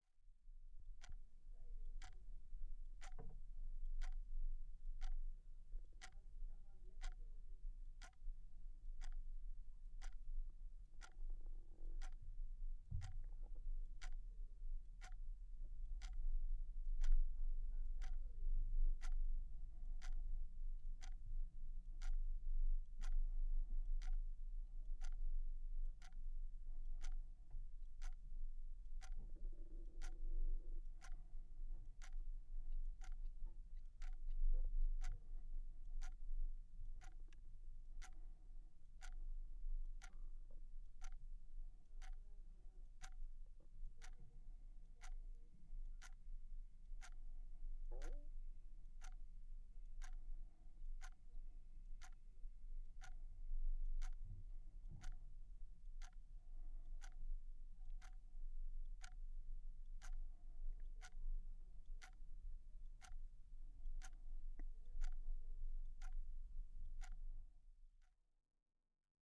钟表 " 小时钟滴答作响
描述：一个小型模拟闹钟，大约一分钟。 用RØDENT2A录制。
标签： 滴答滴答 滴答 时间滴答滴答 时钟 手表 时钟 弗利
声道立体声